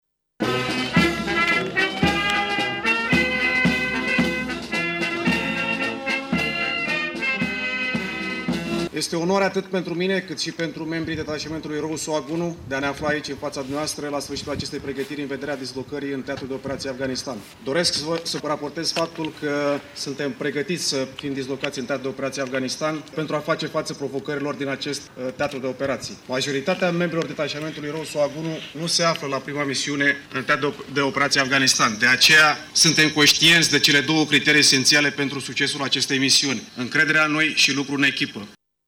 Ceremonia de plecare a avut loc, în această dimineaţă, la sediul Unităţii Militare 01010 din Tîrgu Mureş în prezenţa Ministrului Apărării Naţionale, Mircea Duşa.